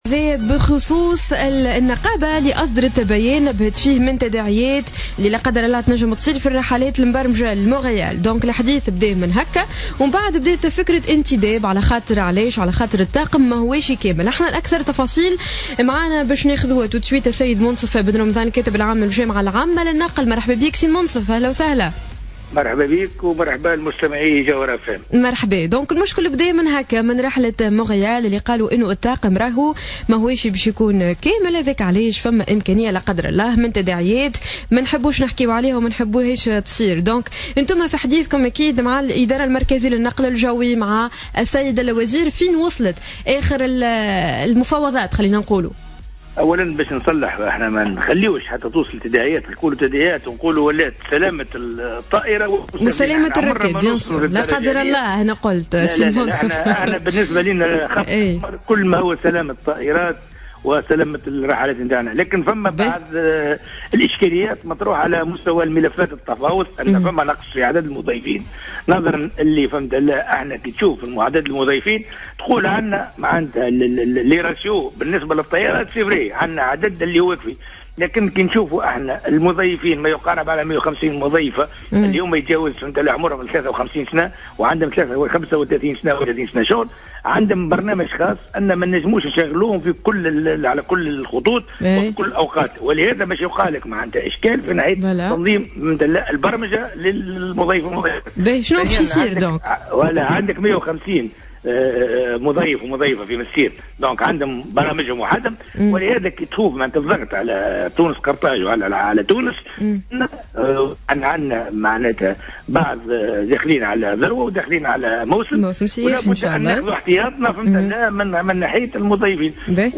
في مداخلة لها على الجوهرة اف ام